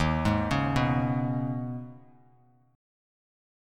Eb7sus4#5 Chord